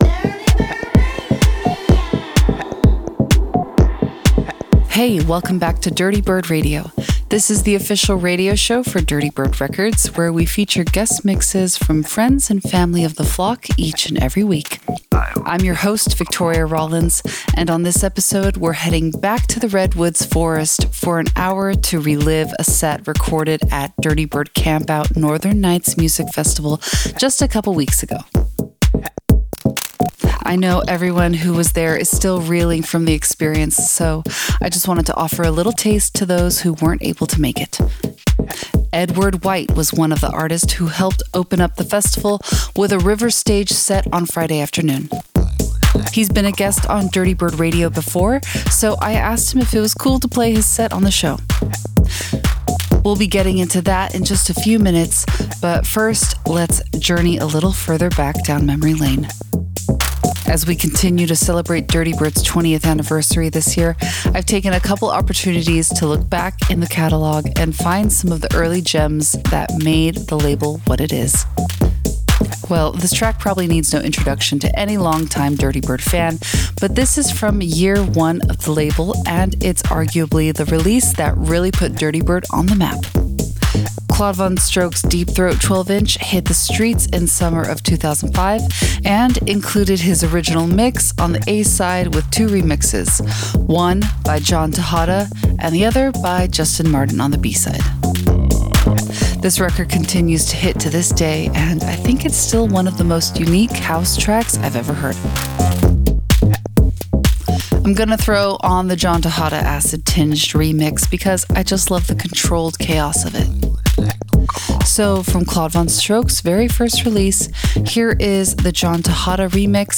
blending house, techno and heavy doses of UKG